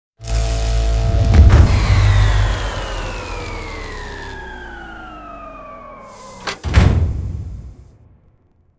PowerOff.wav